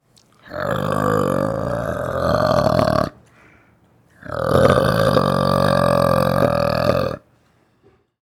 Бульканье в горле у питомца